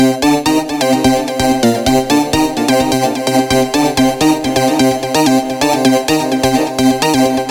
标签： 128 bpm Electronic Loops Synth Loops 1.26 MB wav Key : C
声道立体声